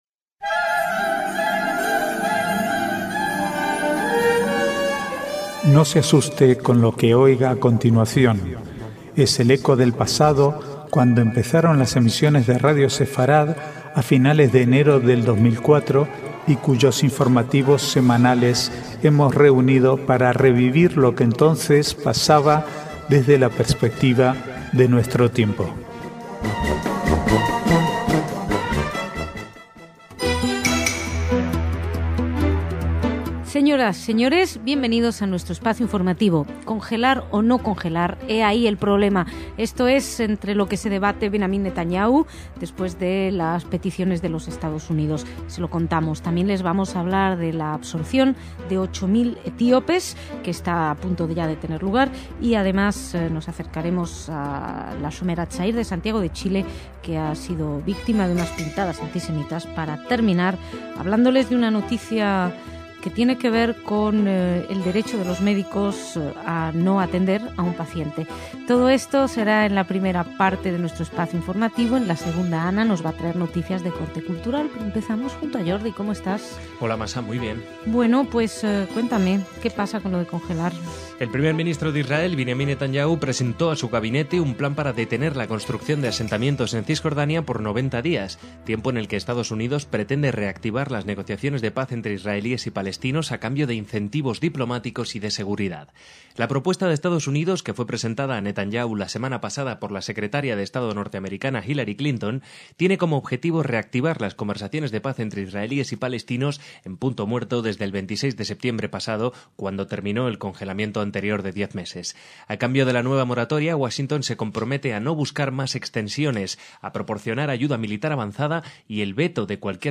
Archivo de noticias del 16 al 19/11/2010